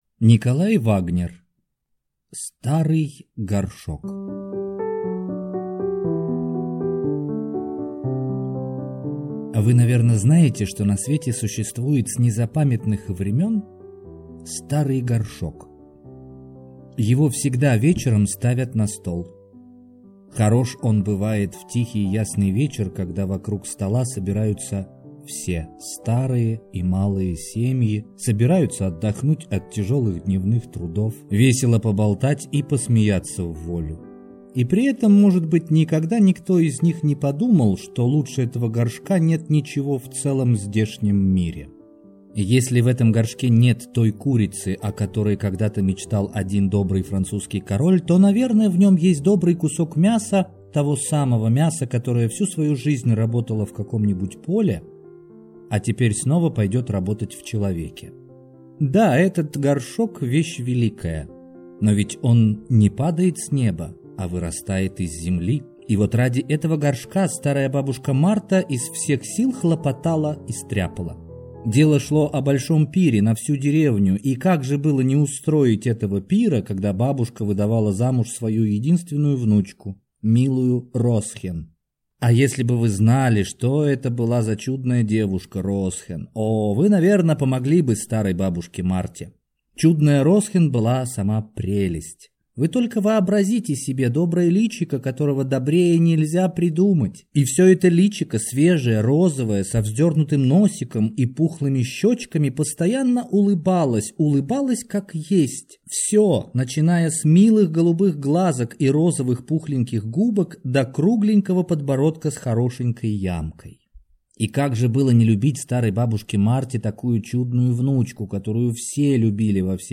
Аудиокнига Старый горшок | Библиотека аудиокниг